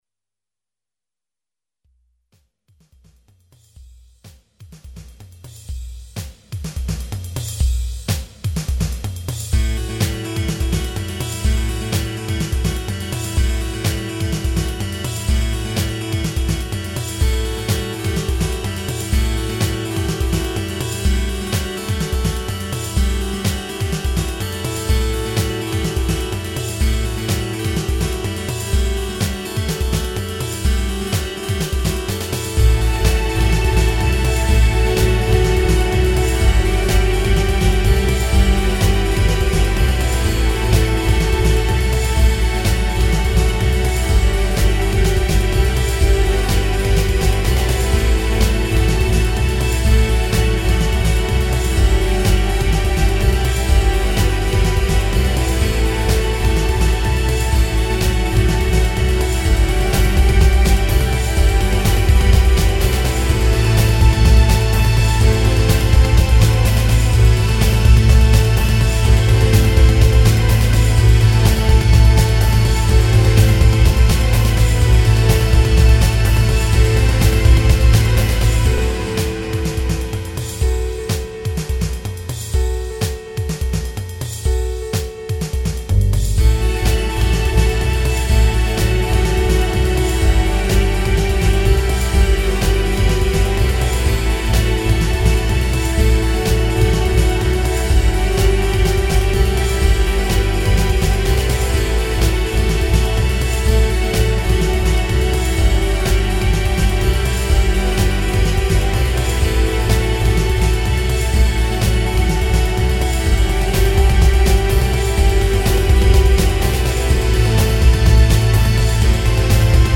Melodic Rock
'05 Remix EQ